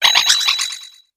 Grito de Indeedee hembra.ogg
Grito_de_Indeedee_hembra.ogg